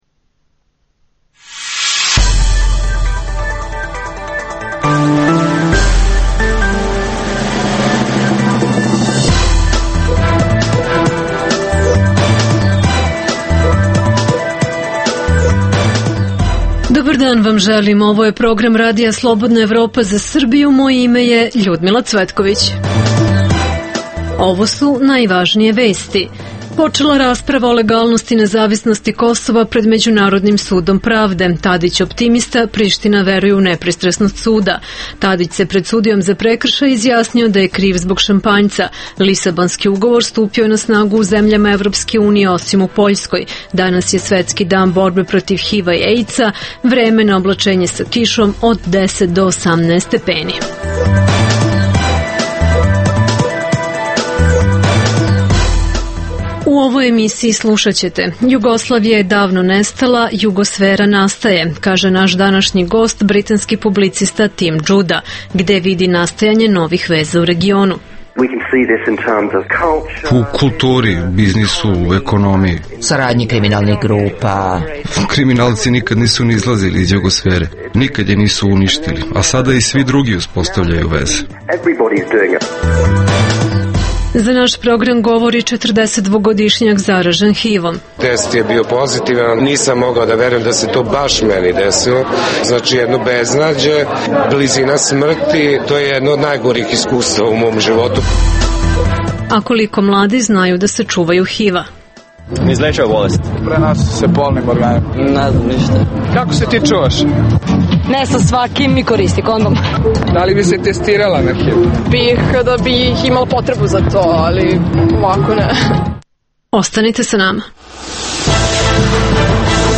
Čućete od našeg izveštača prve akcente sa rasprave o legalnosti nezavisnosti Kosova pred Međunarodnim sudom pravde u Hagu.